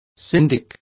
Complete with pronunciation of the translation of syndics.